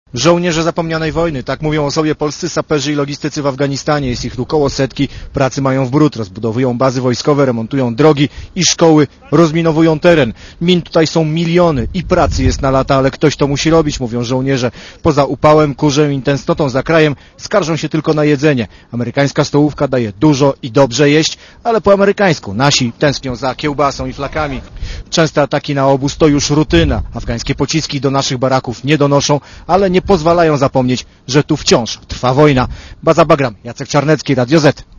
W Afganistanie jest reporter Radia ZET